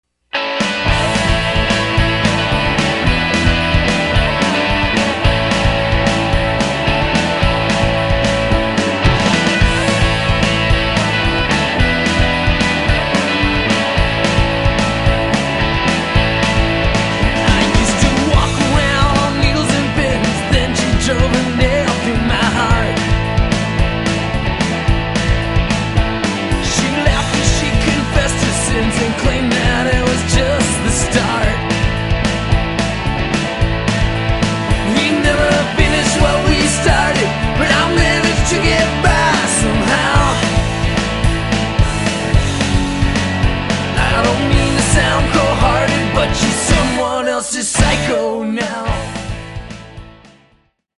--rock